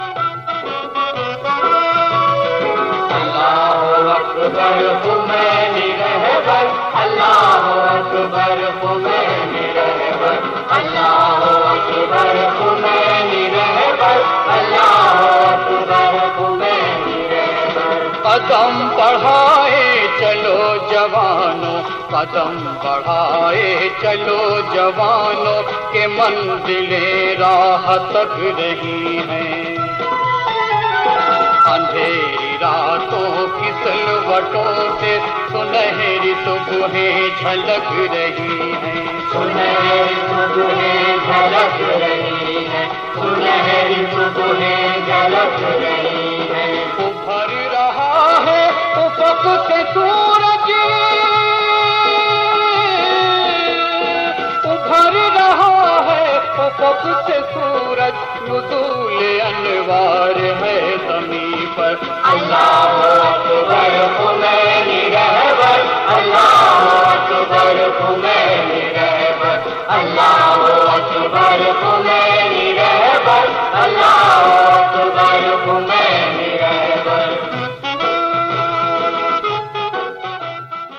ترانہ - اللہ و اکبر خمینی رہبر